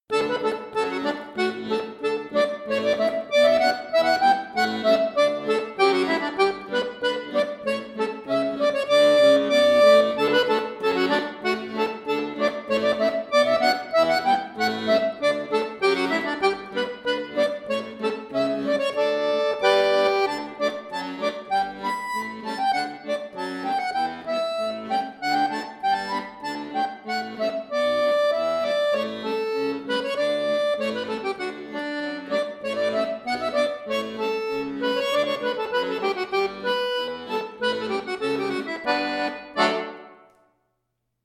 Irish
Folk